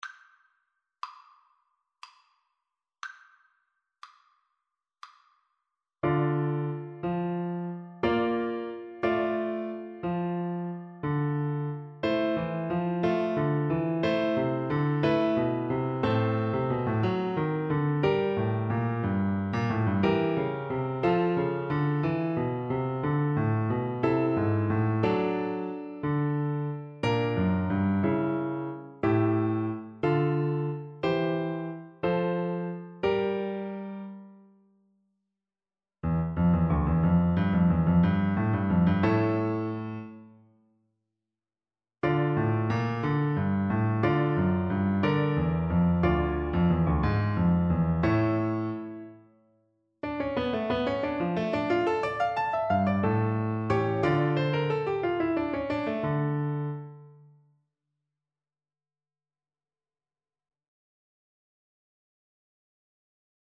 Bassoon
D minor (Sounding Pitch) (View more D minor Music for Bassoon )
Allegro giocoso (View more music marked Allegro giocoso)
9/8 (View more 9/8 Music)
Classical (View more Classical Bassoon Music)